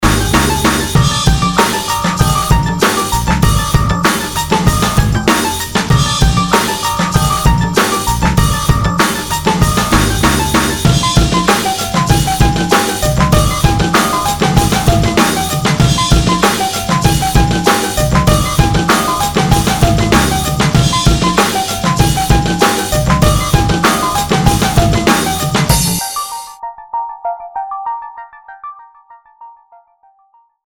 full mix, 60 & 30 edit version include